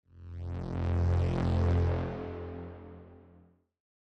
griffin_armor_transition.mp3